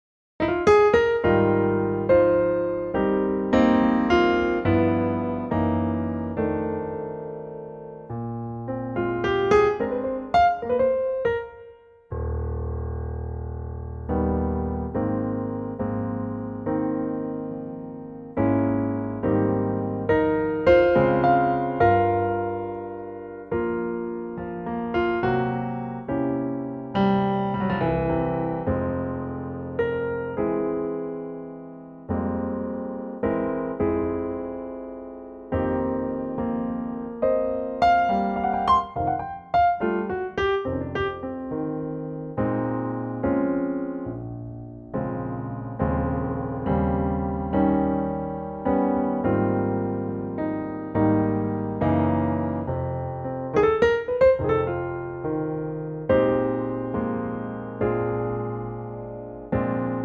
key - Ab - vocal range - Bb to Db
in a lovely piano only arrangement